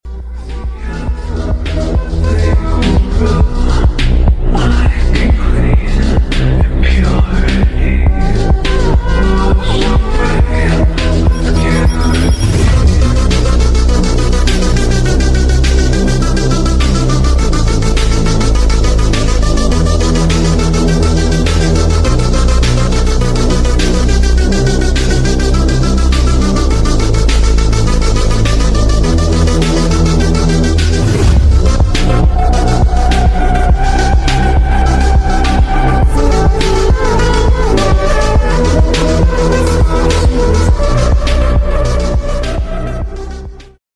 Yamaha Xmax 250 tech max sound effects free download